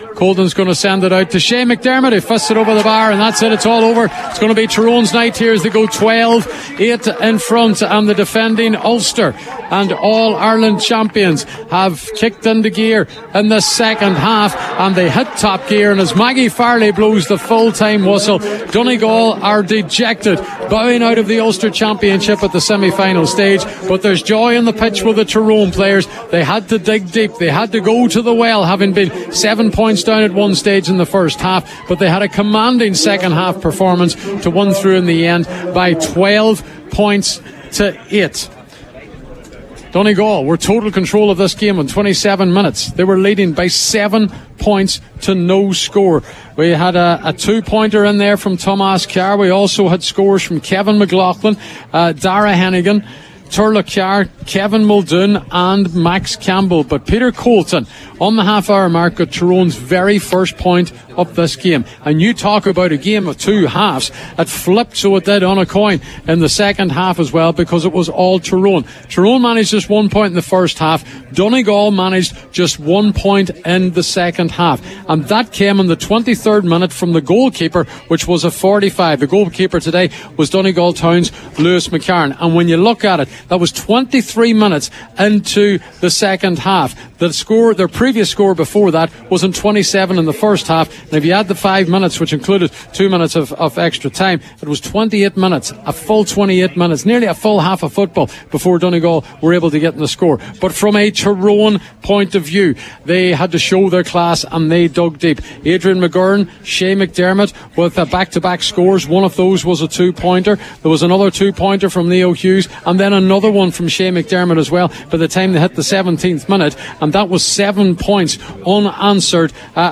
Post-Match Reaction
live at full time in Convoy